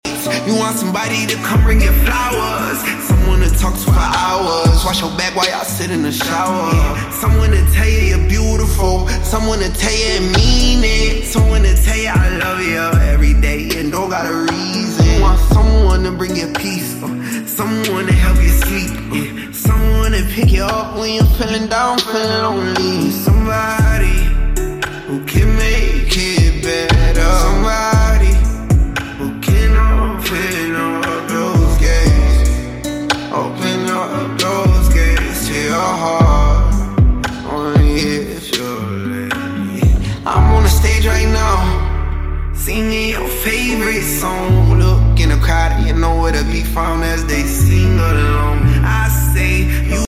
8D AUDIO